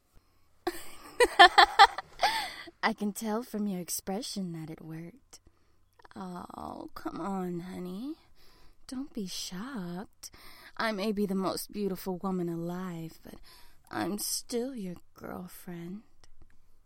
After You Beautify Her – No Enhancements
Beauty-Sample-No-Enhance.mp3